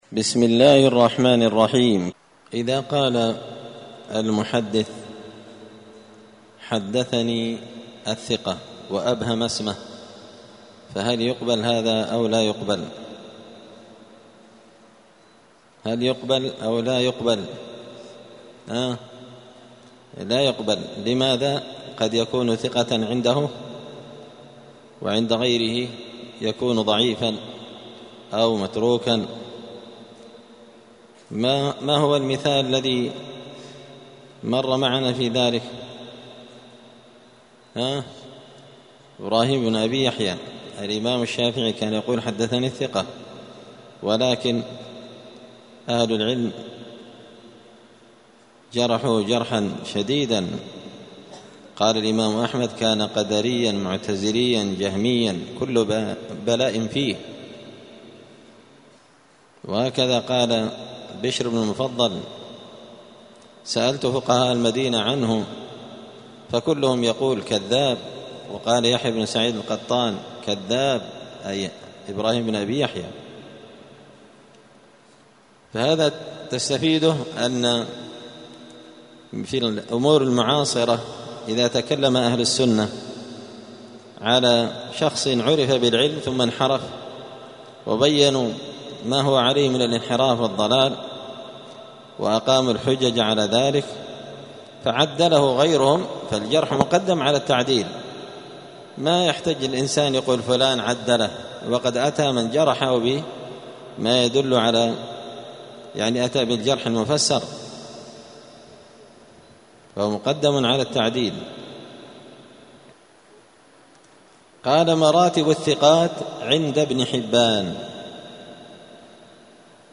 المحرر في الجرح والتعديل الدرس الثالث والأربعون (43) مراتب الثقات عند ابن حبان
43الدرس-الثالث-والأربعون-من-كتاب-المحرر-في-الجرح-والتعديل.mp3